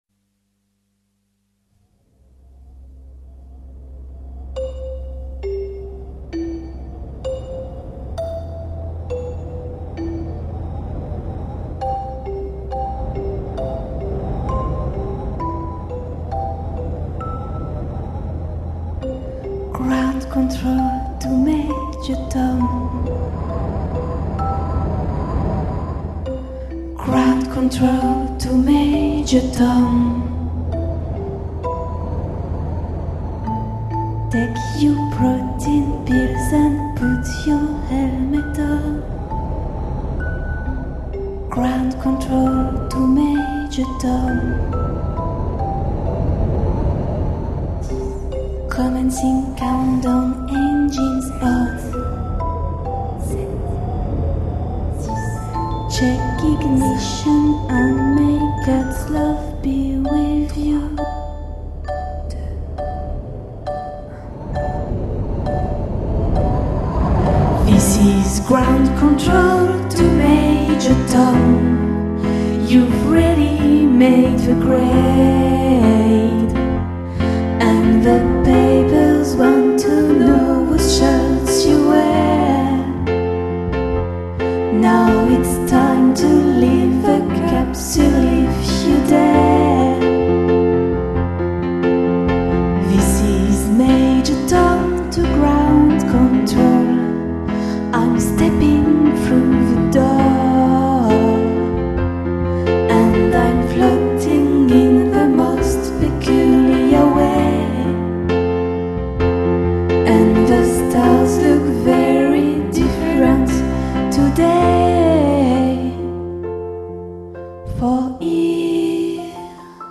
voix, claviers, guitares,basse et programmation batterie